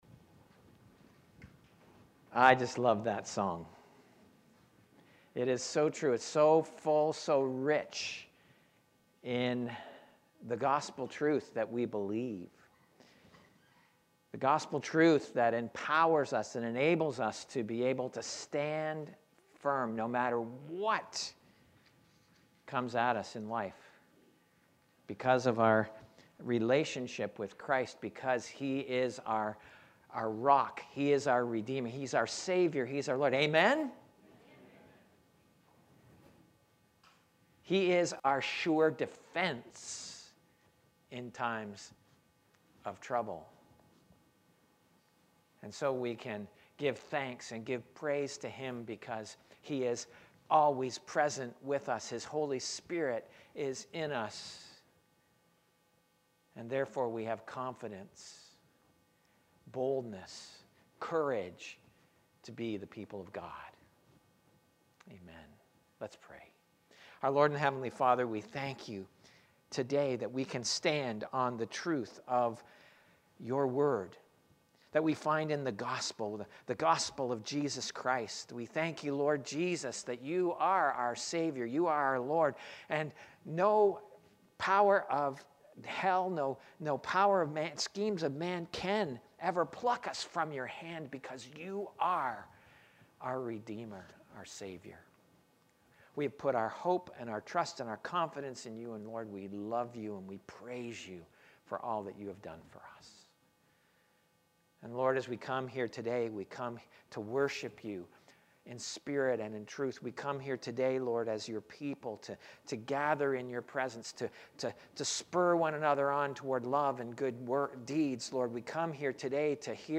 Sermons | Edmison Heights Baptist